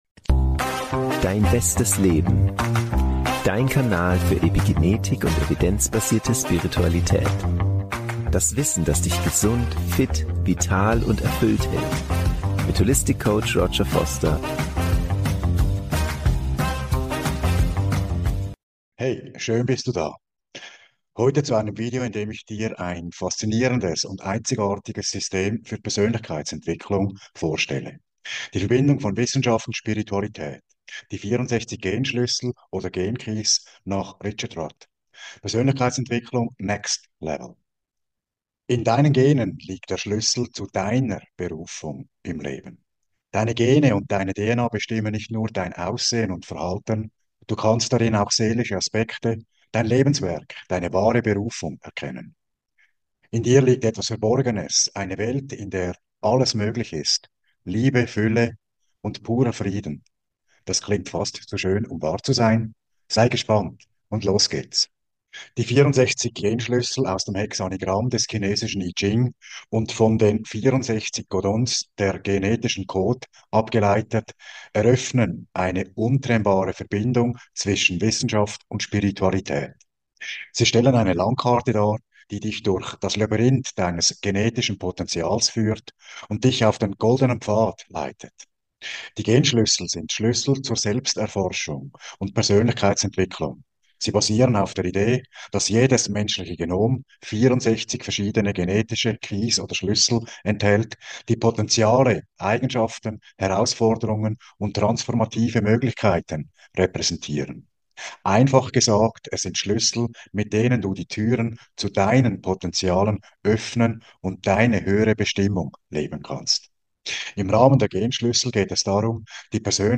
Das Interview wurde für den Selbstbestimmungs-Kongress aufgenommen, der Menschen das Wissen in den großen Lebensbereichen vermittelt, mit denen sie ihr Leben selbstbestimmt gestalten können.